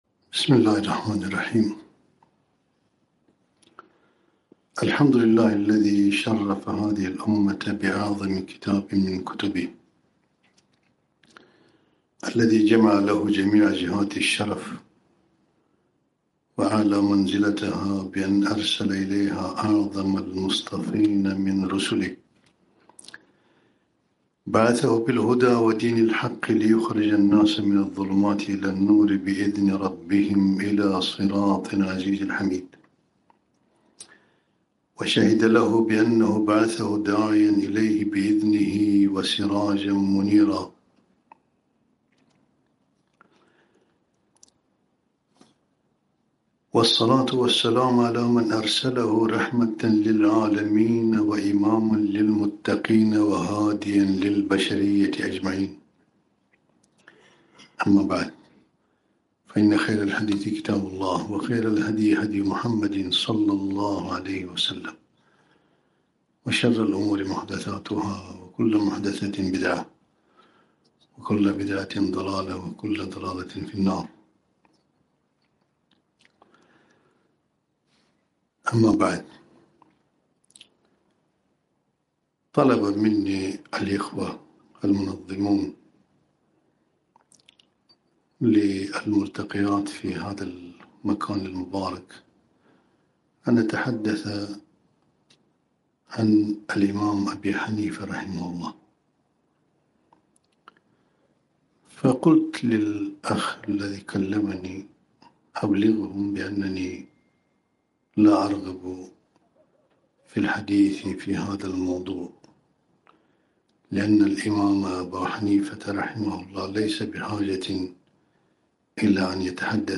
محاضرة - الإمام أبو حنيفة بين الغلو والجفاء